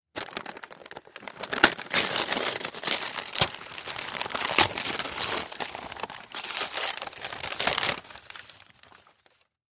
Звук распаковки подарка (сдираем оберточную бумагу)